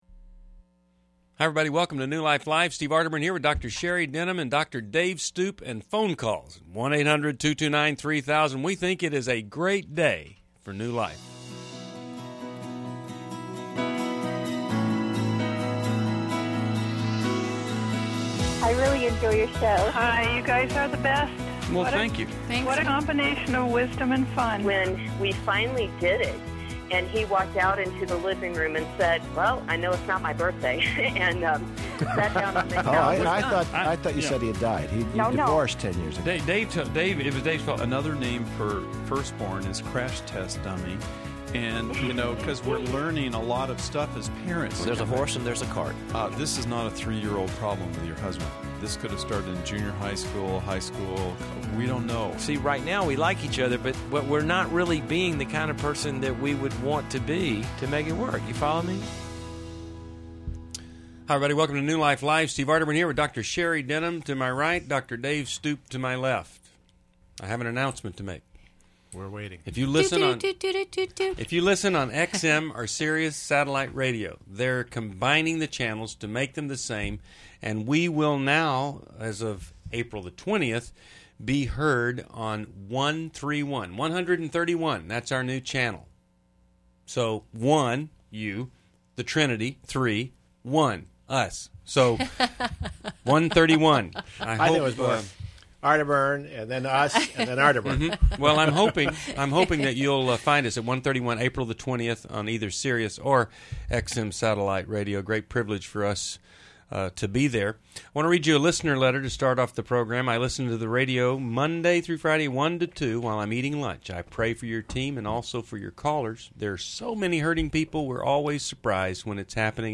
Explore boundaries, marriage, and parenting challenges on New Life Live: April 15, 2011. Get insights from experts on tough caller questions and healing resources.